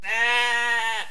دانلود صدای حیوانات جنگلی 57 از ساعد نیوز با لینک مستقیم و کیفیت بالا
جلوه های صوتی